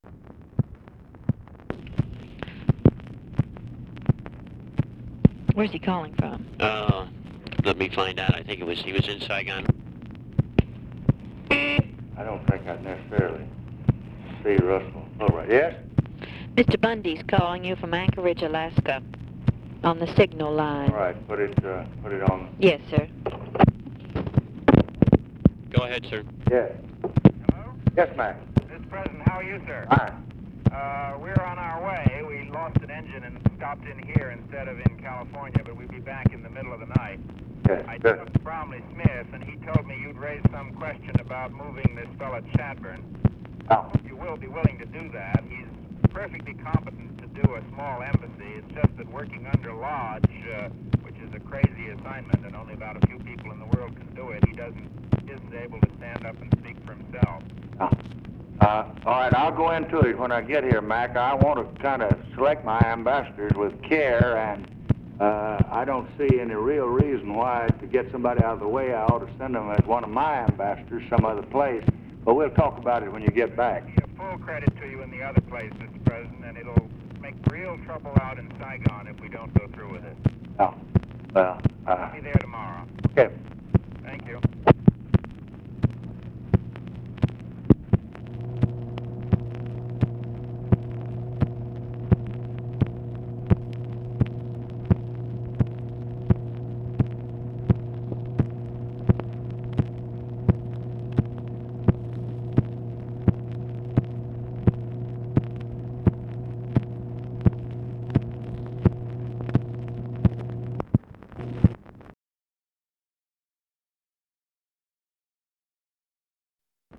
Conversation with MCGEORGE BUNDY, OFFICE SECRETARY, SIGNAL CORPS OPERATOR and OFFICE CONVERSATION, February 15, 1966
Secret White House Tapes